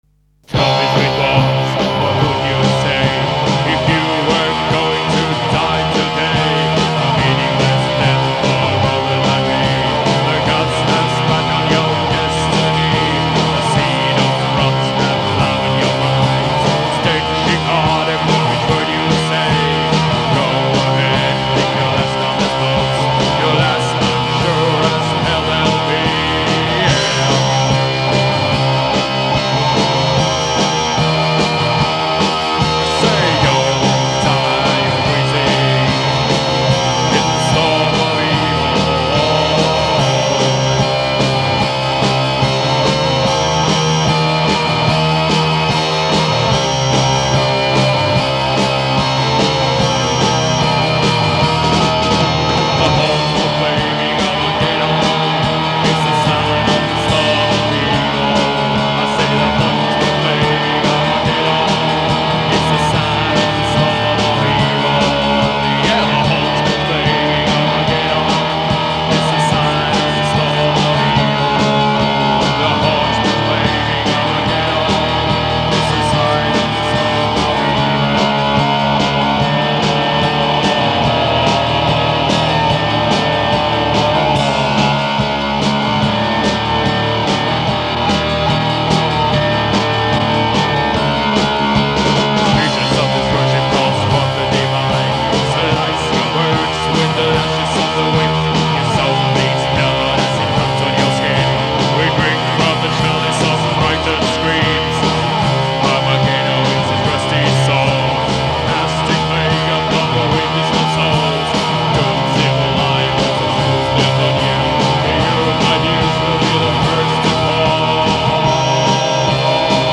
Жанр: Pagan Black Metal